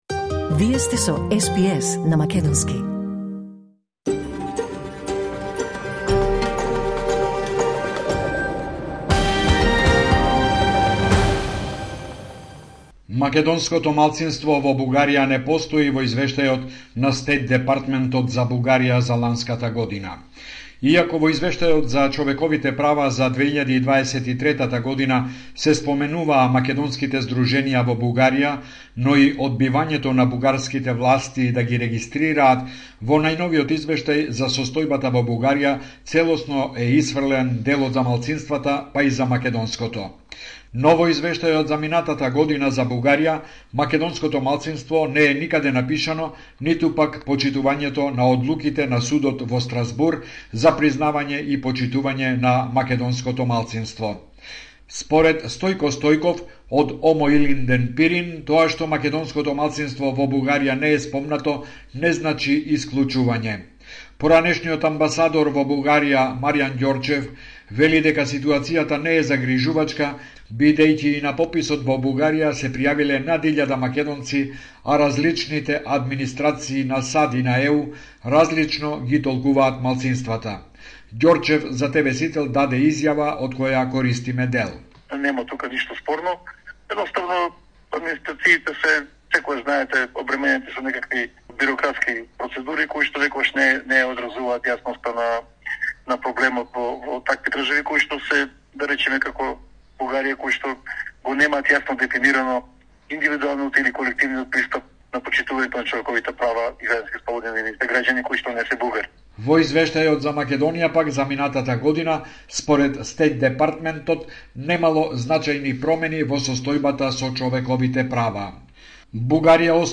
Homeland Report in Macedonian 15 August 2025